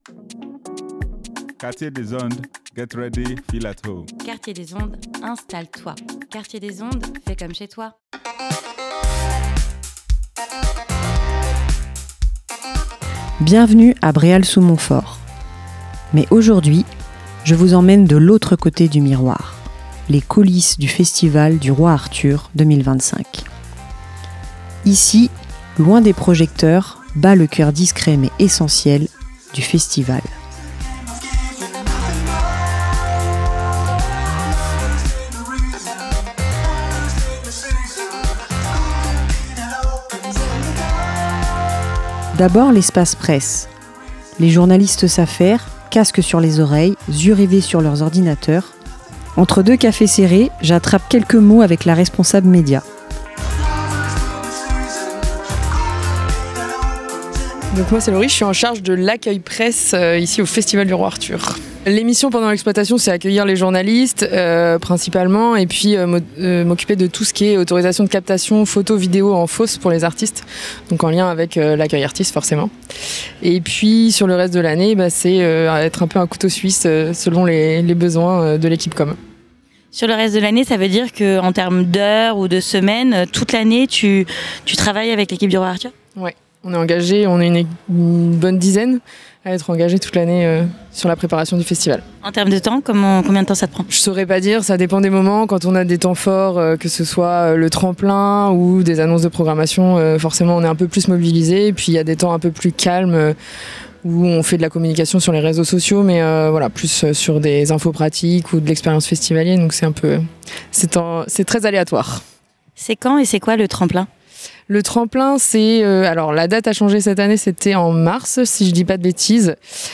Cette année, Quartier des Ondes s’est rendu une nouvelle fois au festival du Roi Arthur mais s'est intéressé cette fois-ci à la question de l’engagement bénévole sur le festival. Comme pour nous, les bénévoles sont le cœur même de la tenue du festival chaque année, engagée à l’année ou sur le temps du festival nous sommes allés à leur rencontre pour un reportage joyeux et spontané !